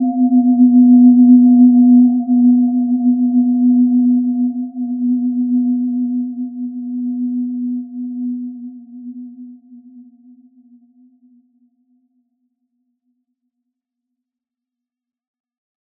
Gentle-Metallic-3-B3-mf.wav